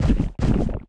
drop_2.wav